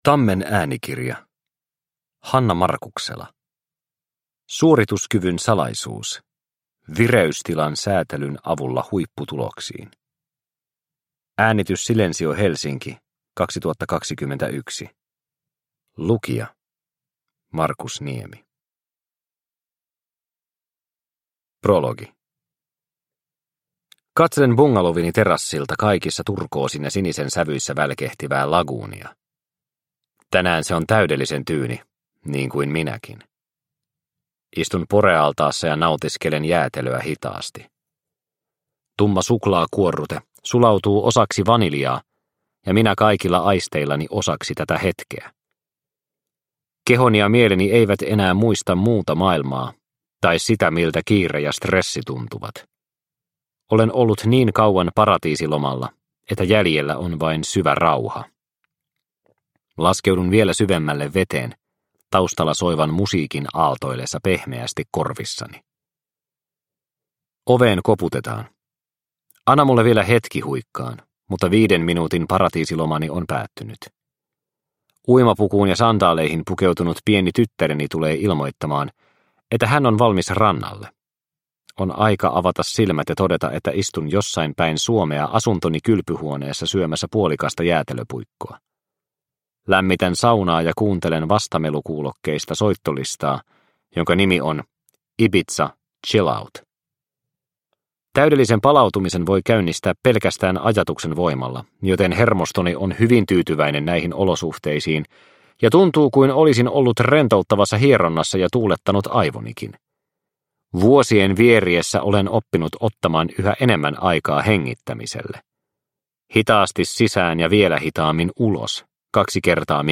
Suorituskyvyn salaisuus – Ljudbok – Laddas ner